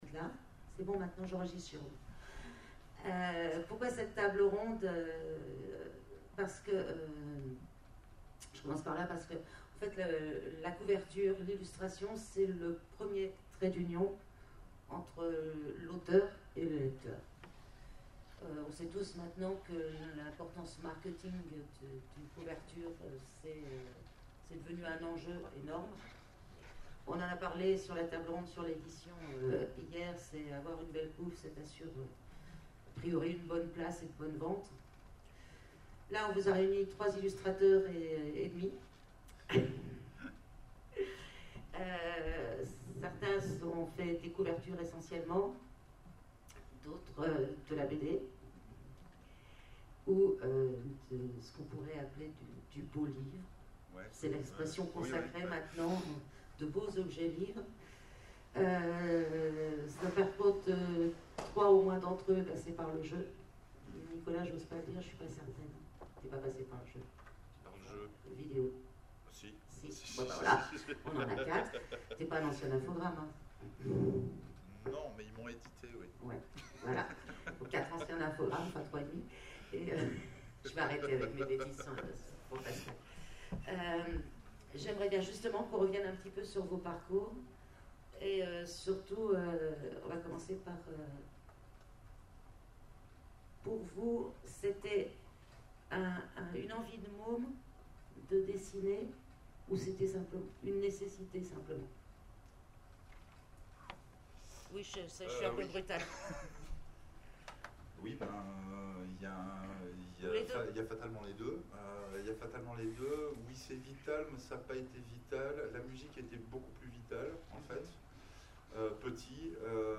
Les Oniriques 2017
Conférence